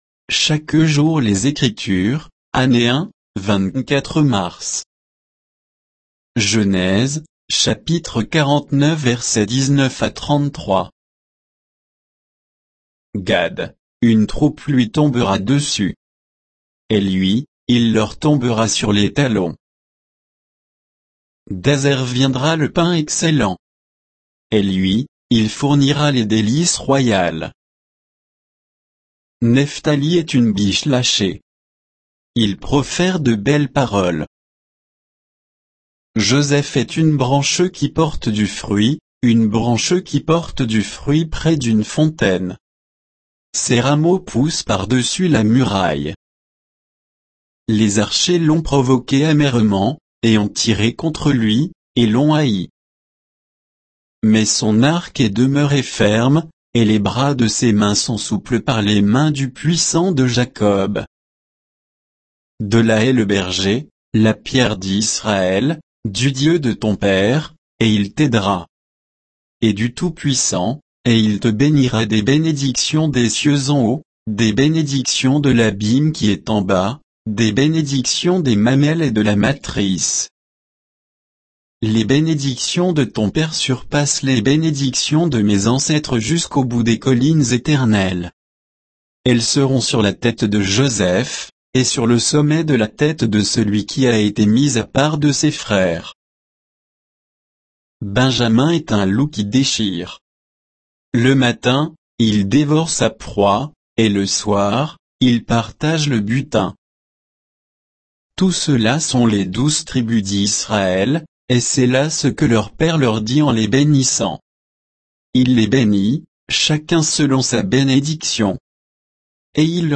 Méditation quoditienne de Chaque jour les Écritures sur Genèse 49, 19 à 33